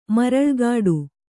♪ maraḷgāḍu